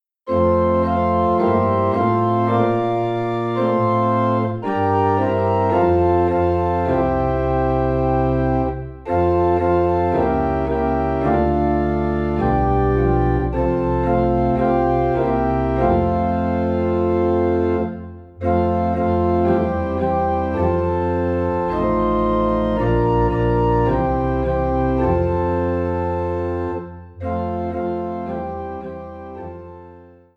3 in F